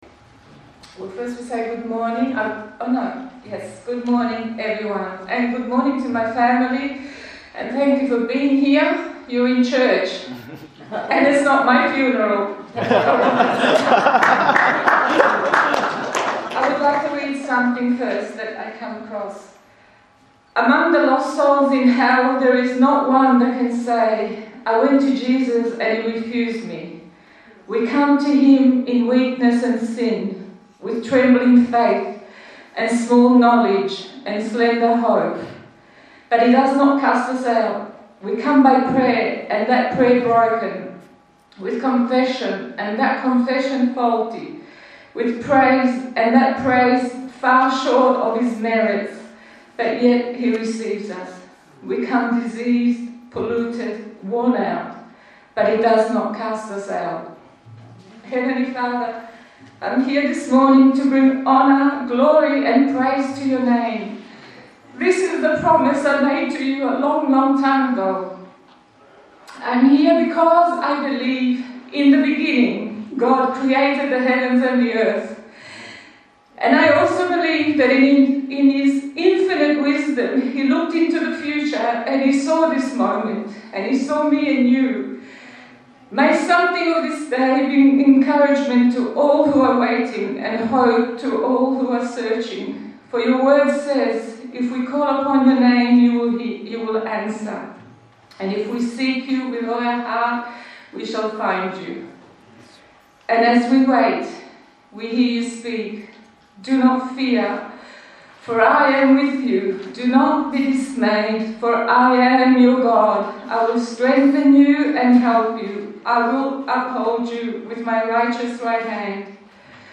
Testmony